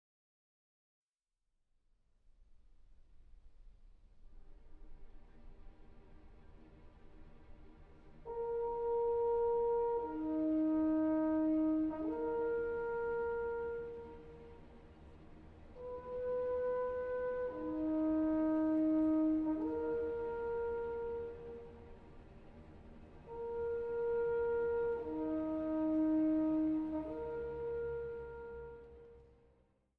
Bewegt, nicht zu schnell